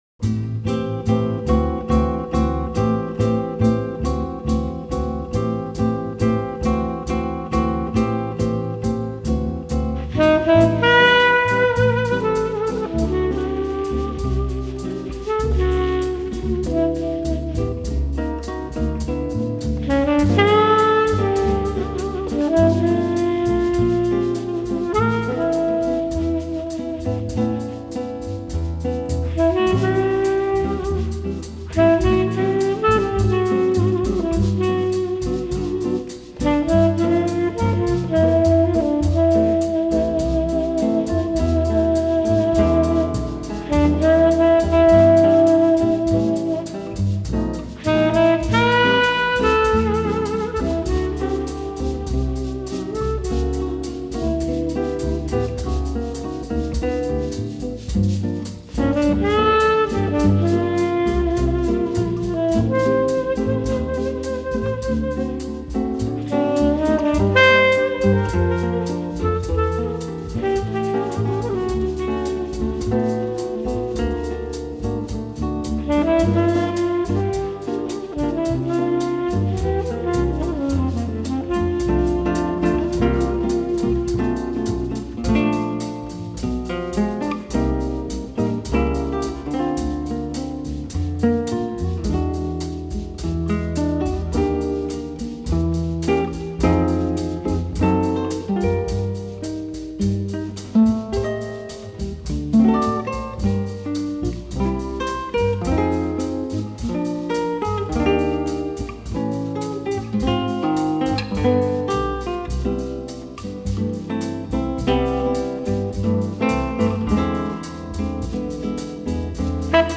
Звучит почти как колыбельная...